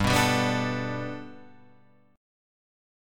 G Major Add 9th